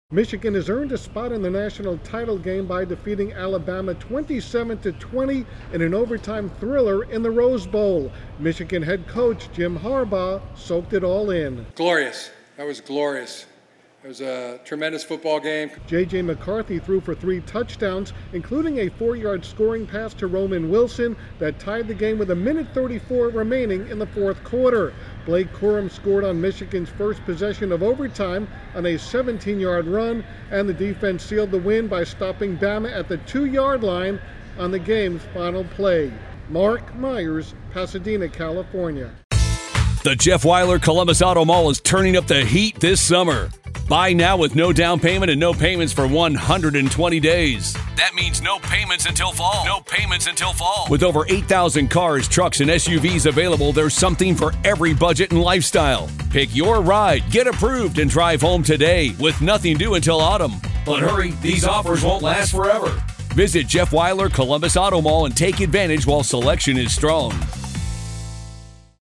Michigan knocks off Alabama in overtime. Correspondent